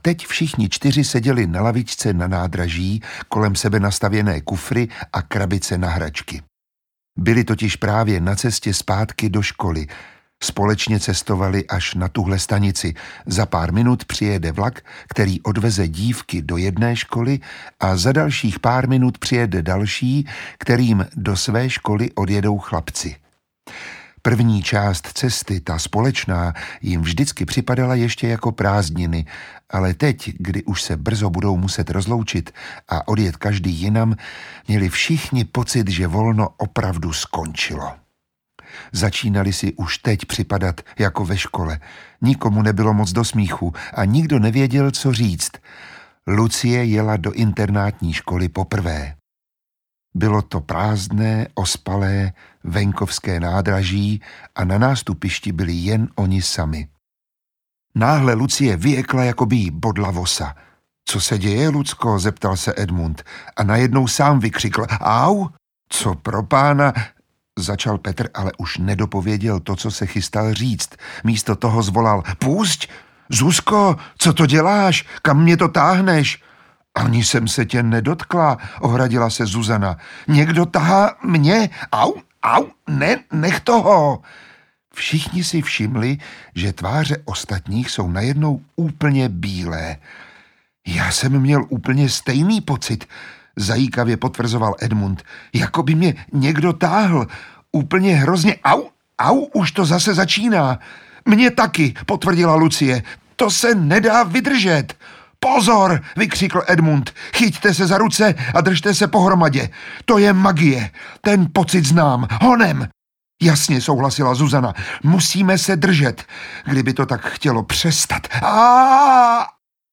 Letopisy Narnie 4 – Princ Kaspian audiokniha
Ukázka z knihy
Podaří se mu s podporou dětí a lva Aslana zachránit Narnii a vrátit jí dávno ztracené časy svobody a štěstí?Nechte se i vy pohltit napínavým příběhem s nečekanými zvraty a zaposlouchejte se do charismatického hlasu Miroslava Táborského, díky němuž před vámi všechny fantastické postavy doslova ožijí.
• InterpretMiroslav Táborský
letopisy-narnie-4-princ-kaspian-audiokniha